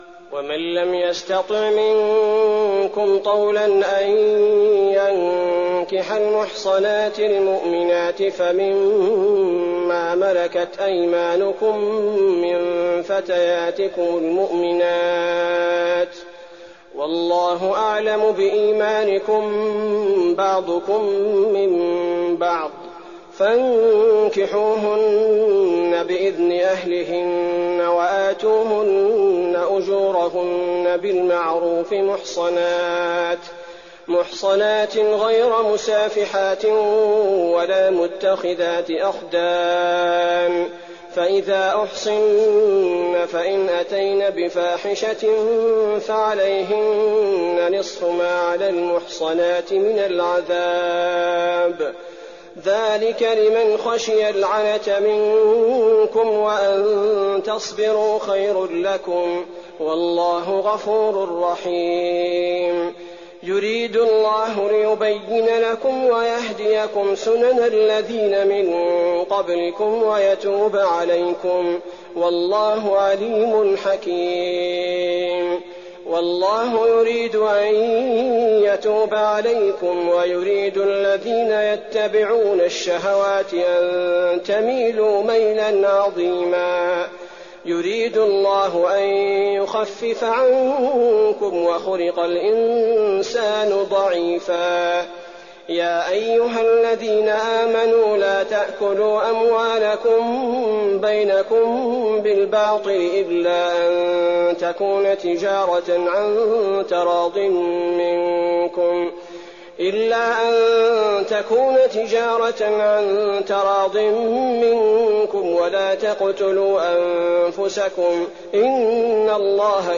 تراويح الليلة الخامسة رمضان 1419هـ من سورة النساء (25-87) Taraweeh 5th night Ramadan 1419H from Surah An-Nisaa > تراويح الحرم النبوي عام 1419 🕌 > التراويح - تلاوات الحرمين